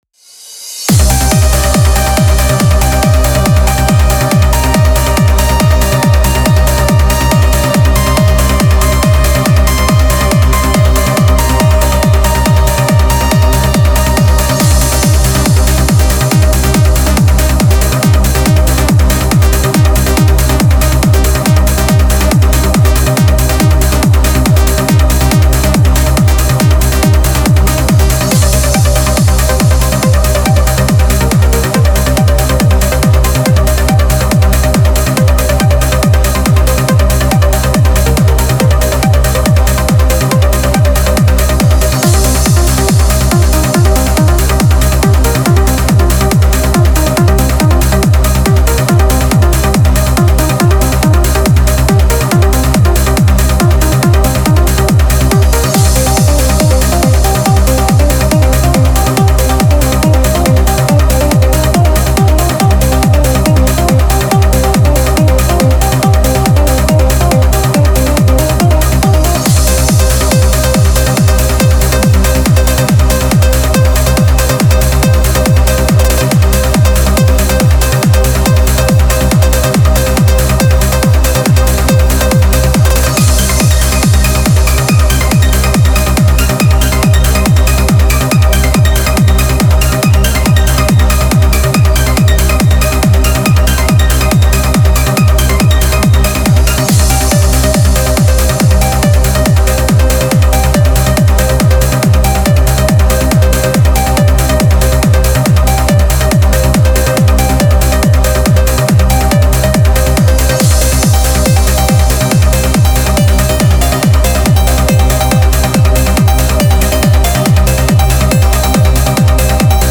Trance Uplifting Trance
Style: Trance, Uplifting Trance